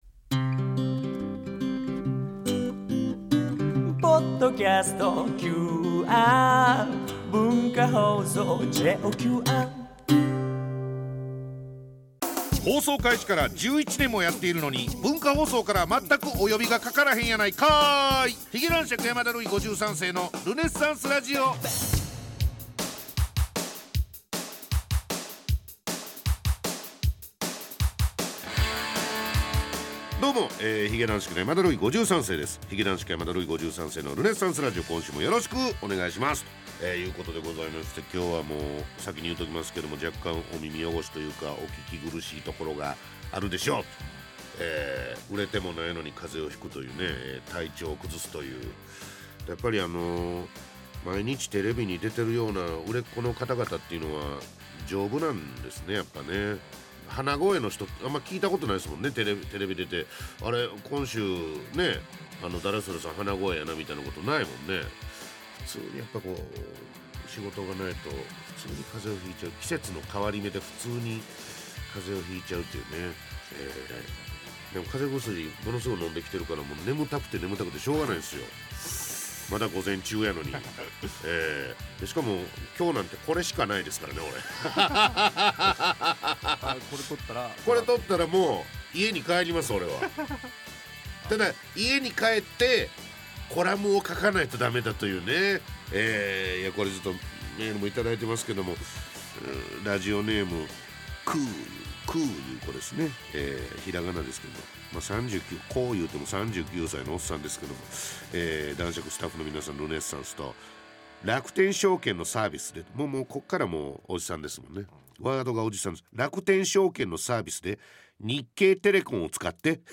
ルネッサ～ンス！！でおなじみの髭男爵・山田ルイ５３世がお送りする 「髭男爵 山田ルイ５３世のルネッサンスラジオ」。